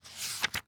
ES_Book Paperback 6 - SFX Producer.wav